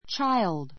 child 小 A1 tʃáild チャ イ るド 名詞 複 children tʃíldrən チ るド レン ❶ （大人に対して） 子供 ✓ POINT 男の子にも女の子にも使い, baby と呼ばれる時期を過ぎた幼児から, 12～13歳 さい 頃 ごろ までの子供を指す. when I was a child when I was a child 私が子供の頃 ころ You are no longer a child.